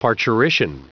Prononciation du mot parturition en anglais (fichier audio)
Prononciation du mot : parturition